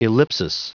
Prononciation du mot ellipsis en anglais (fichier audio)